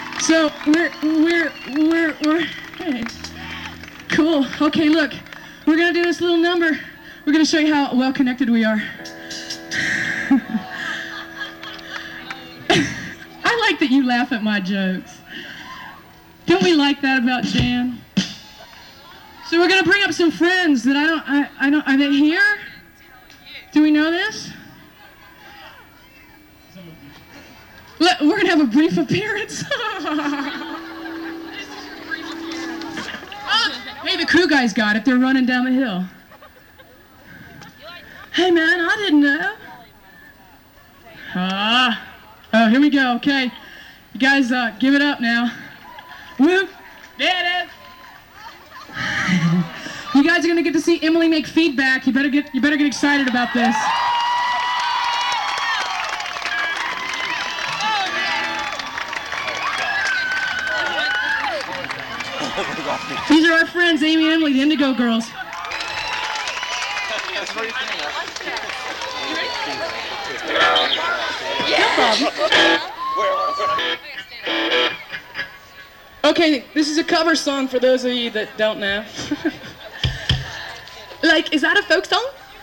lifeblood: bootlegs: 1994-04-30: earth jam - stone mountain, georgia (alternate recording) (24bit 48khz)
08. talking with the crowd (viva la diva) (1:22)